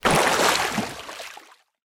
dc0f4c9042 Divergent / mods / Soundscape Overhaul / gamedata / sounds / material / human / step / t_water1.ogg 58 KiB (Stored with Git LFS) Raw History
t_water1.ogg